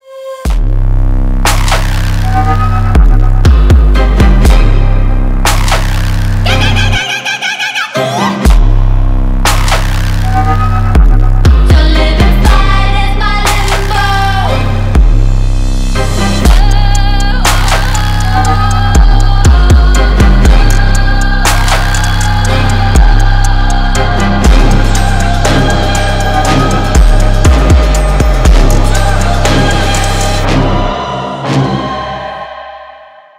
• Качество: 320, Stereo
громкие
мощные
женский голос
мощные басы
Trap
Bass House
Стиль: bass house / trap